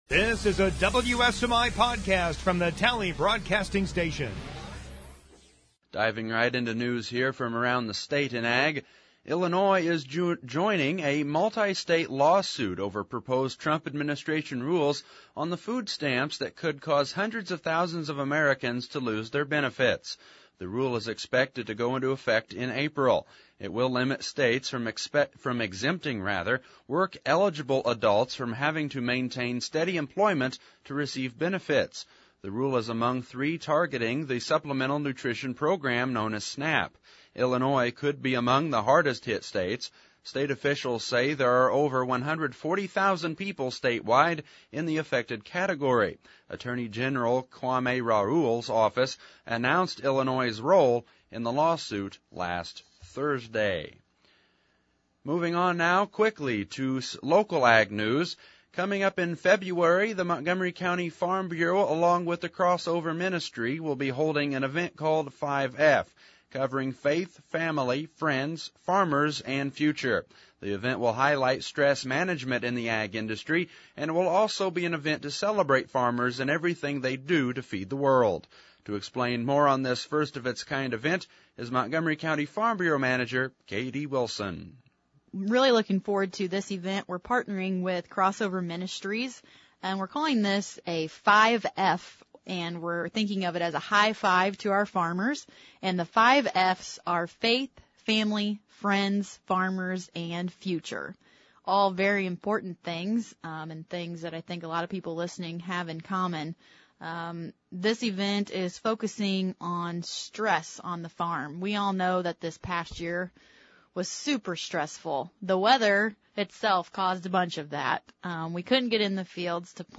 Illinois Join Lawsuit over New Food Stamp Rules 2. Interview w